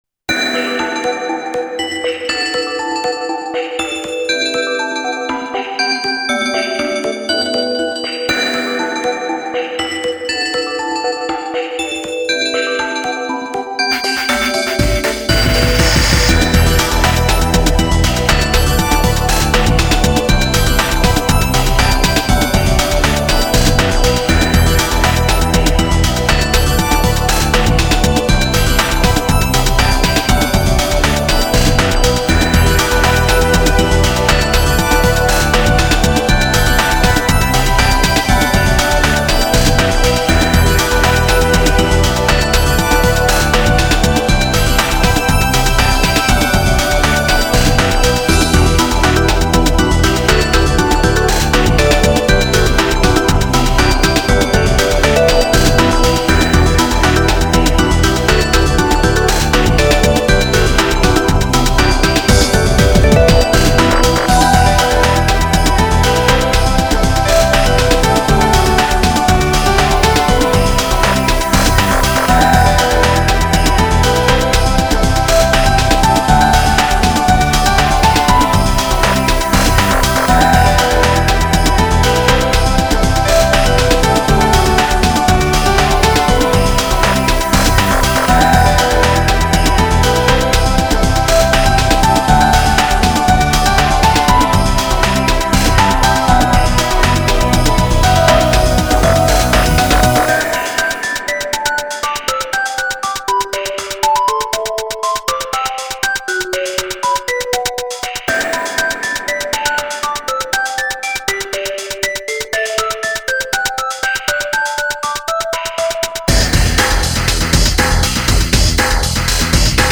フリーのシンセサイザー「Synth1」の使い手として知られ
インスト系をメインとしており、AmbientやTechnoの要素がある作品が多い。
ambient